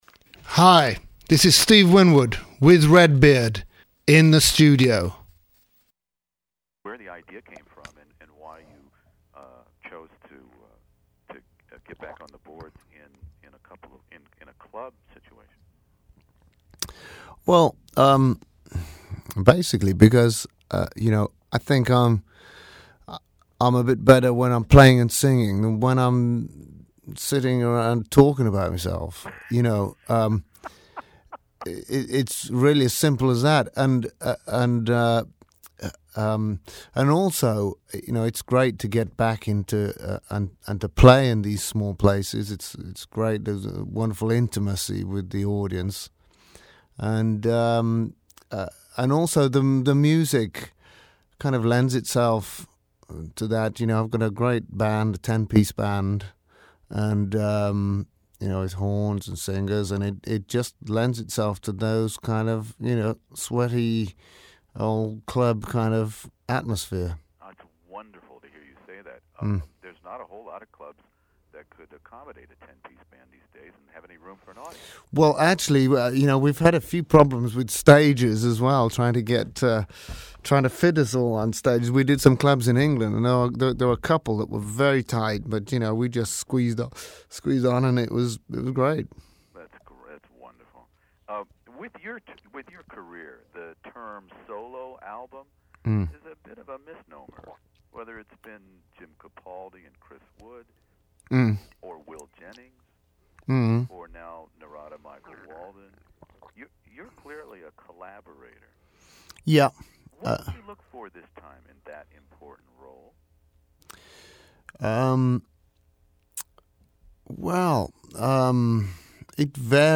Steve Winwood talks about his seventh solo album, Junction Seven , and how a so-called “solo” album inevitably isn’t at some point in the creative process, in my classic rock interview.